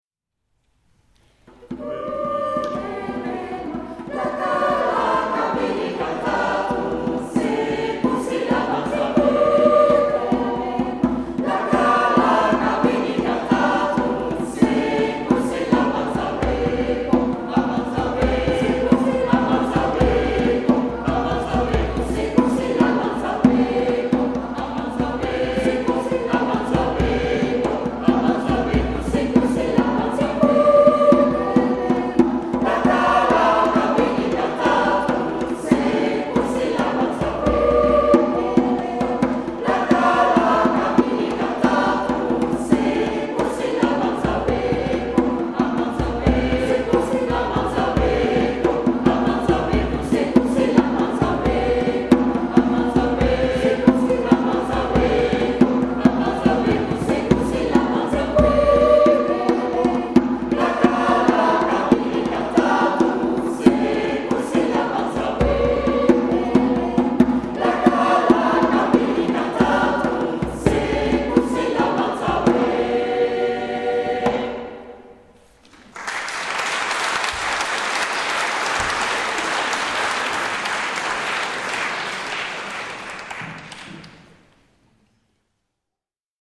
Aperitiefconcert 12 uur
Dit is een trouwlied.